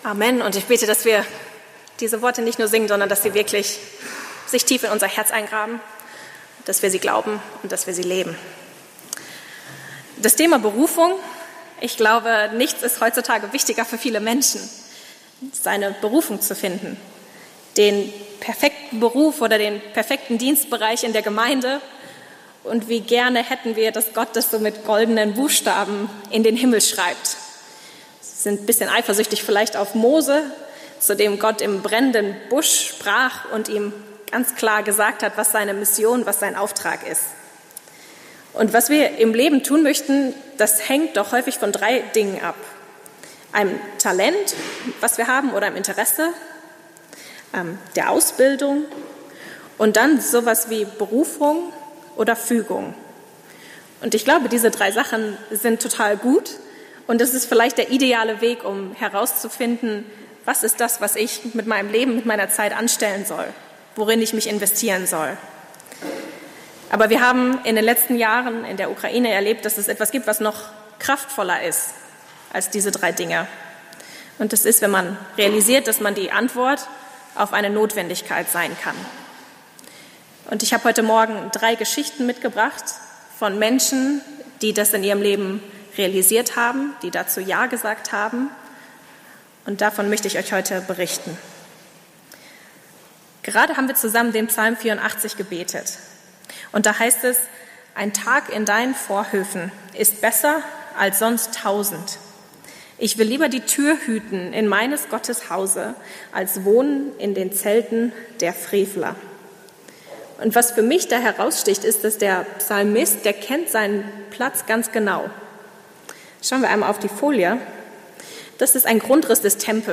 Bericht aus Kiew